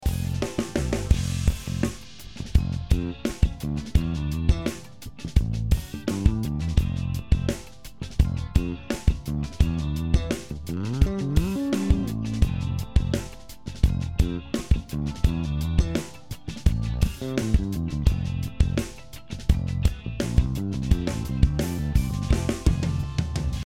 次にSaturn 2をかけた音。
TONEスライダーの低域部分を上げただけあって低域の迫力が出ていますね。
サウンドのカラーもWarm Tubeを選択してます。